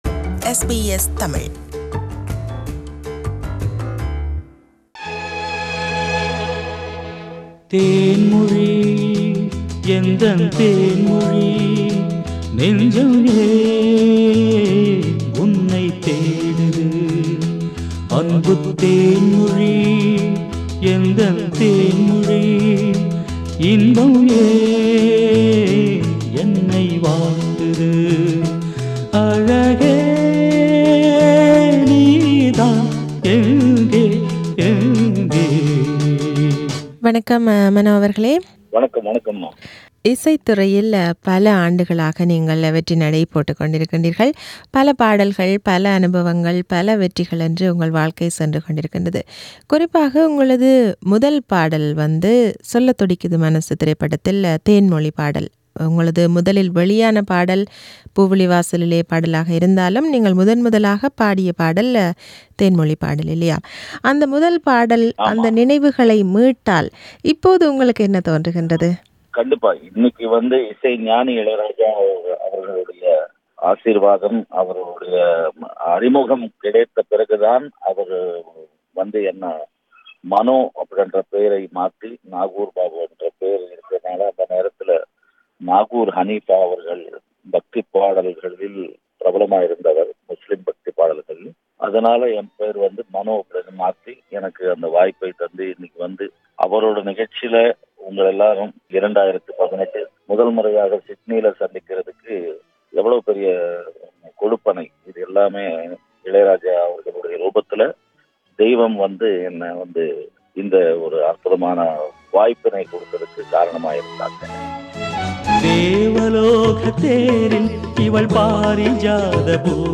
Mano will perform in Raja Rajathaan concert in Sydney. This is an interview with him.